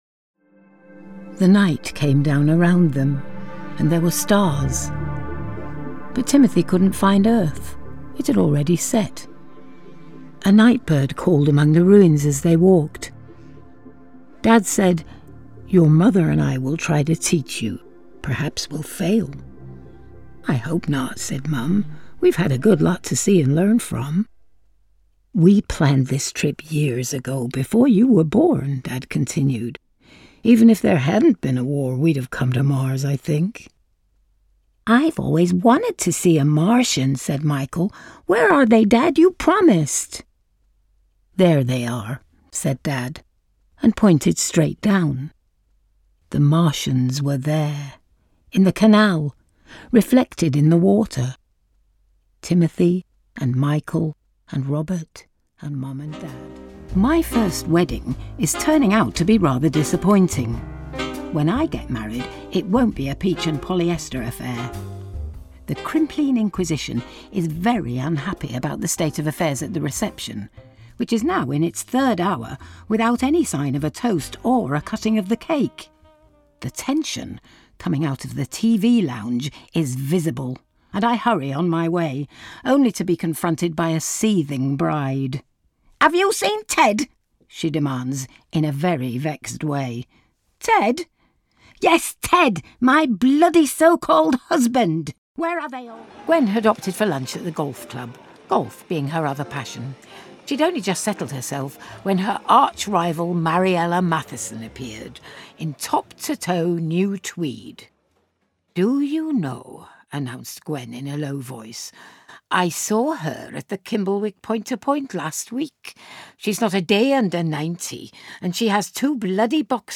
Audiobook Showreel
Female
Yorkshire
Gravitas
Smooth